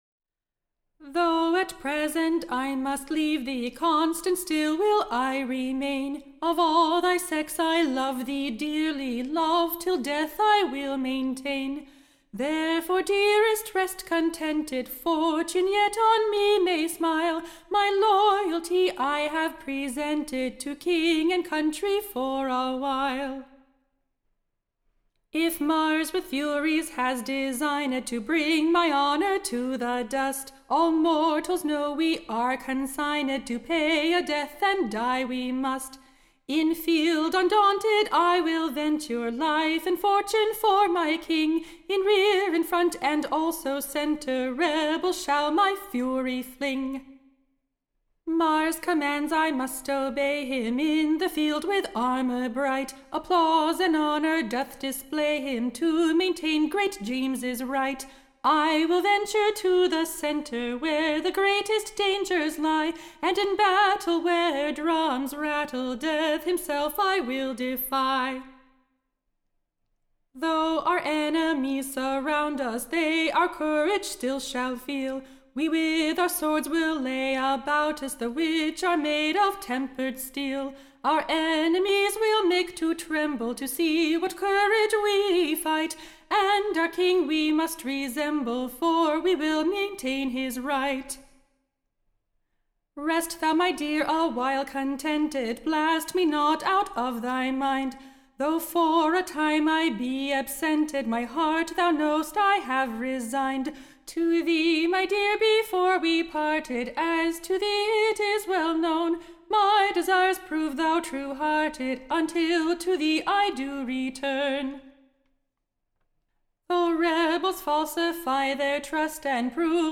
Recording Information Ballad Title REAL REALITY, / OR, / The Souldiers Loyalty. Tune Imprint To the Tune of, The Doubting Virgin.